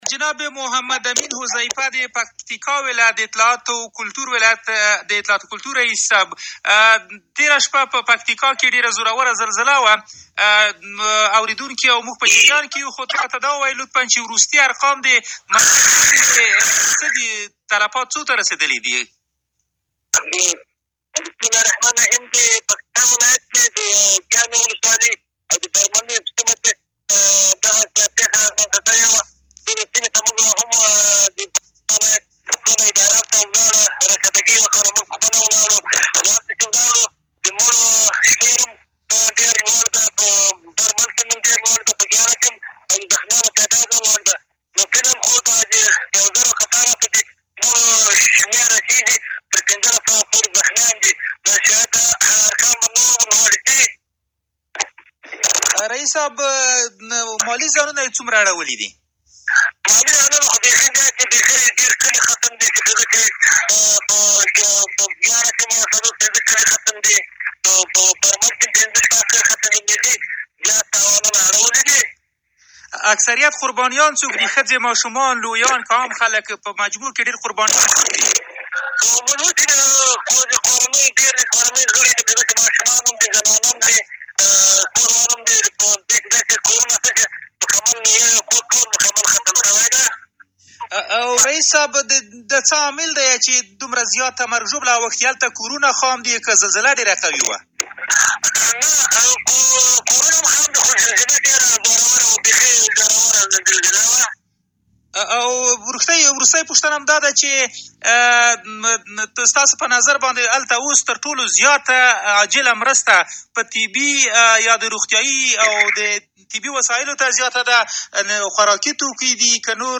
به گزارش رادیو دری؛ محمد امین حذیفه رئیس اطلاعات و فرهنگ ولایت پکتیکا در گفت و گوی اختصاصی با رادیو دری افزود:در زلزله شب گذشته در این ولایت بیش از یکهزار نفر کشته و یکهزار و پانصد نفر زخمی شده اند.